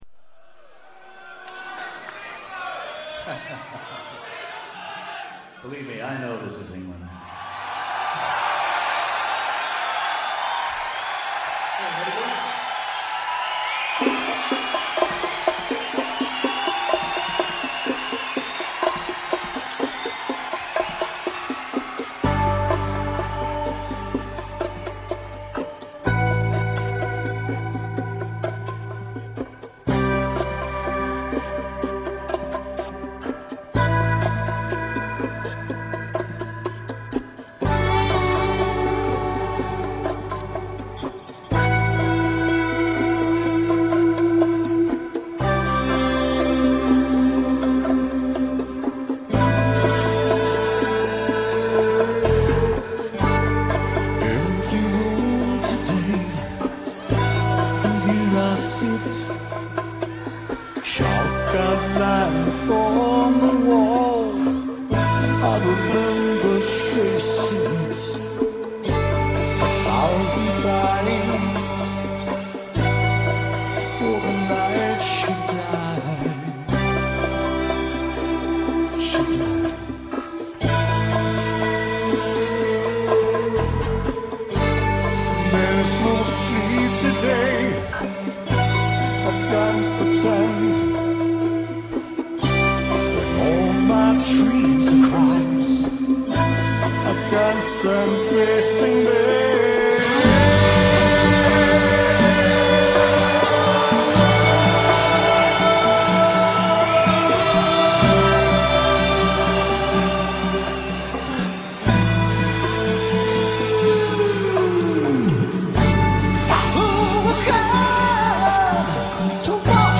(live)
Liveaufnahme vom Londoner Promogig am 20. Oktober 1994